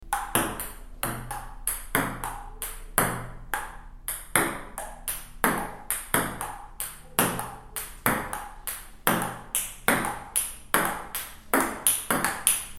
Jugando Ping Pong V.1: Efectos de sonido deportes
Este efecto de sonido ha sido grabado para capturar la agilidad y la precisión del sonido de la pelota de ping pong golpeando las raquetas y la mesa, proporcionando un sonido claro y distintivo que se integrará perfectamente en tus proyectos.
Tipo: sound_effect
Jugando Pin Pong.mp3